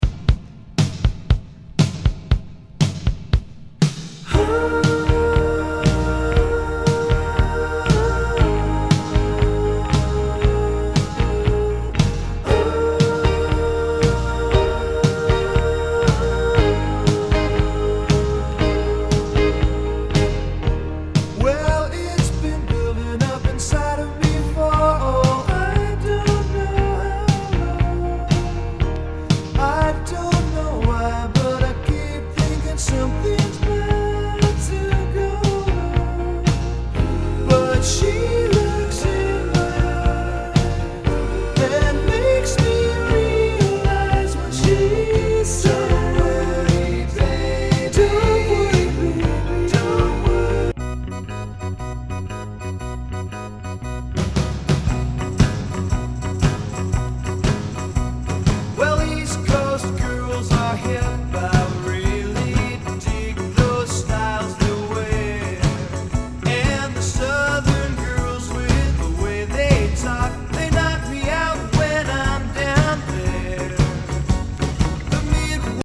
大御所サーフ・ミュージック・グループ